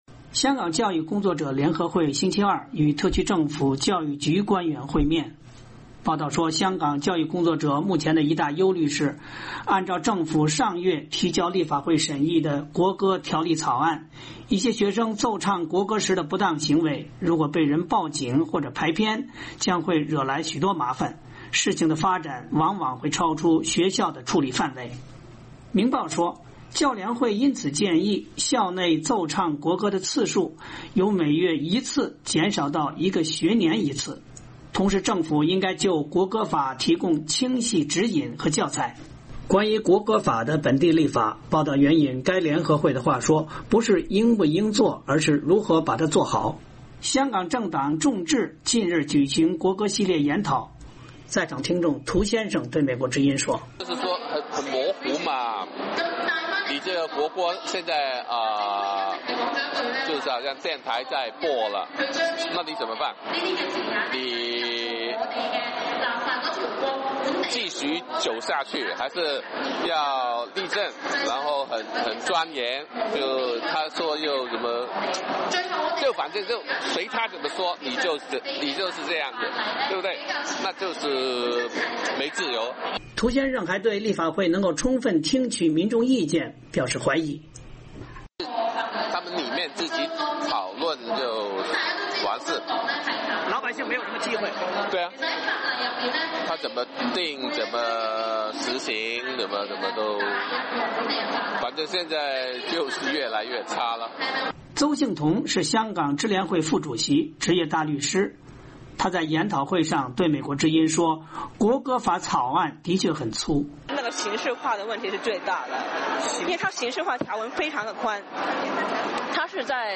九龙美孚的国歌法研讨会现场